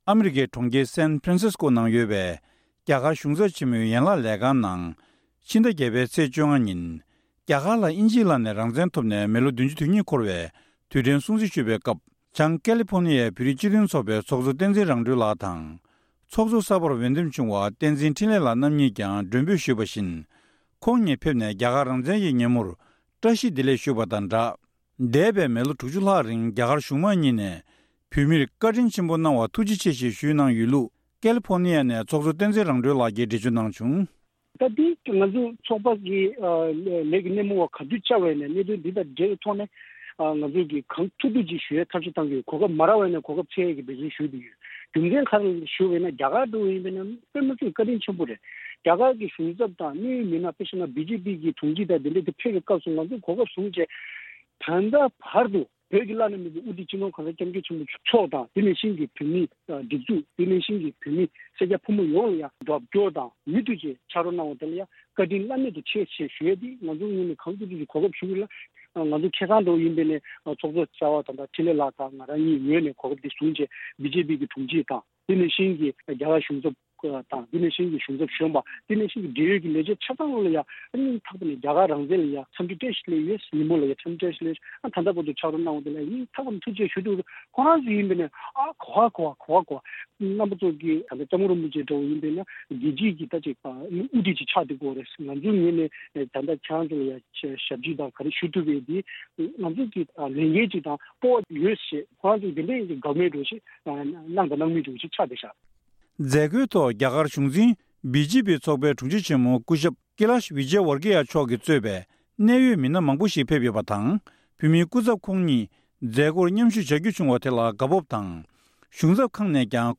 བཀའ་འདྲི་ཞུས་ནས་ཕྱོགས་སྒྲིགས་ཞུས་པ་ཞིག་གསན་རོགས་གནང་།།